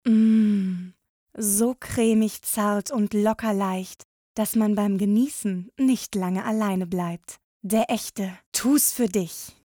sehr variabel, hell, fein, zart
Jung (18-30)
Vocals (Gesang), Scene